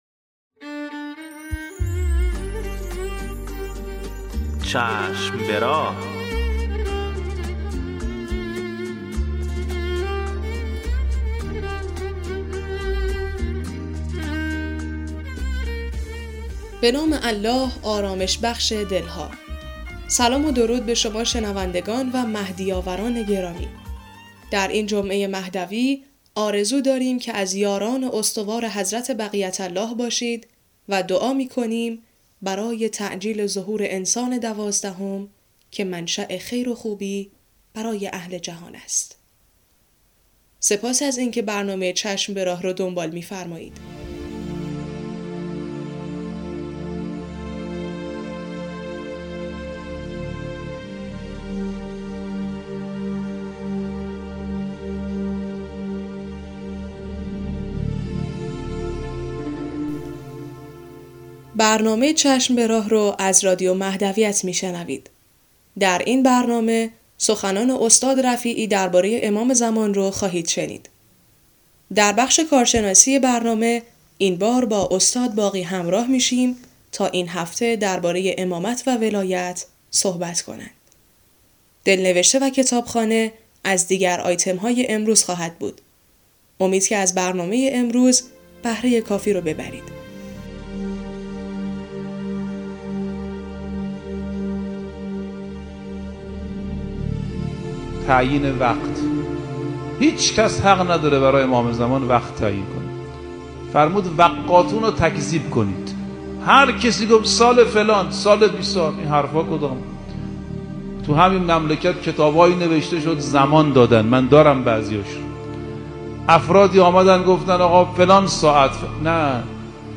قسمت صد و شصتم مجله رادیویی چشم به راه که با همت روابط عمومی بنیاد فرهنگی حضرت مهدی موعود(عج) تهیه و تولید شده است، منتشر شد.